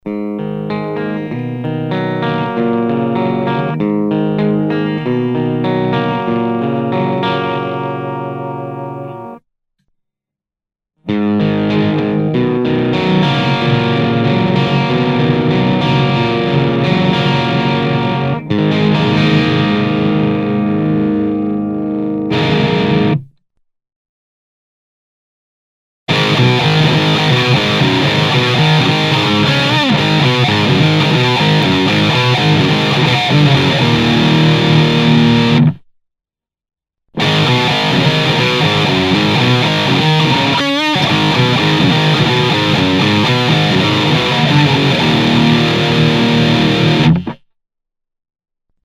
TMとHGの違いはゲインが20％アップ。
オーバドライブはGAIN7 Bass6 Middle6 Treble7プリアンプブースターON時はGAIN5
ピックアップはEMG81です。
こちらはエレハモと違う意味で中音域が少ない分高音域がヌケます。
歪み系エフェクターと組み合わせると個性が強くハイゲインなプリ管です。